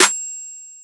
TM SNARE 4.wav